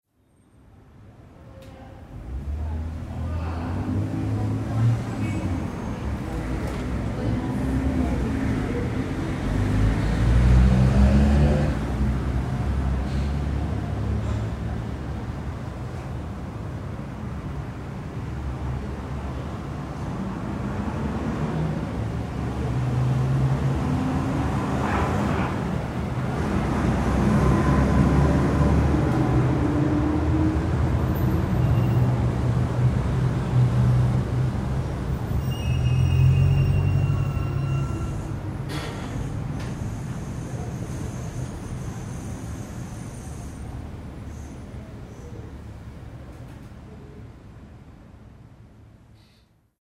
Ambiente interior de bar con pasadas de coches en el exterior